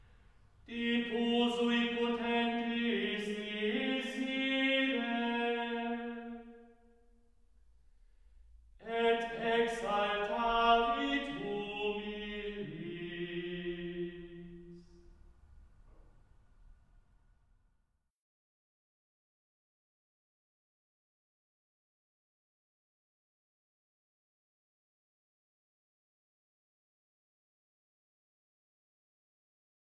Cembalo, Bibelregal, Zuberbier-Orgel Dudensen, Gesang